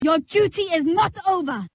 Your Duty Is Not Over Sage Voice Line